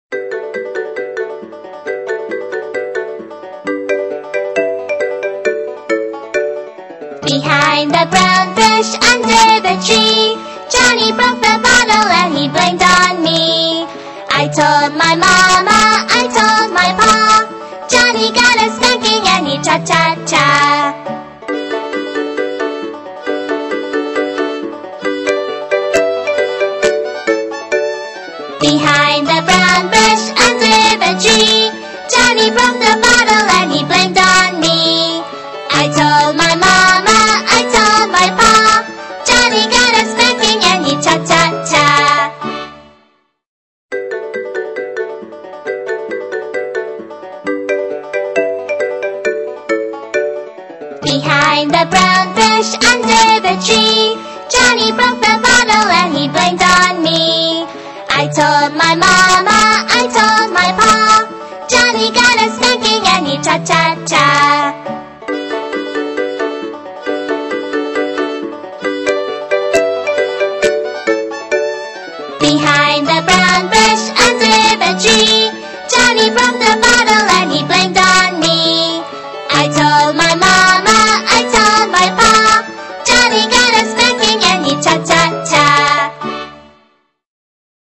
在线英语听力室英语儿歌274首 第17期:Behind the Brown Bush的听力文件下载,收录了274首发音地道纯正，音乐节奏活泼动人的英文儿歌，从小培养对英语的爱好，为以后萌娃学习更多的英语知识，打下坚实的基础。